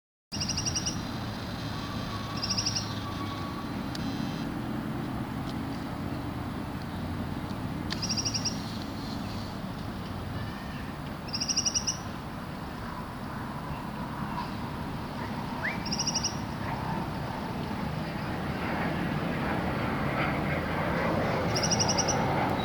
Ferruginous Pygmy Owl (Glaucidium brasilianum)
Life Stage: Adult
Location or protected area: Lago de Regatas
Condition: Wild
Certainty: Recorded vocal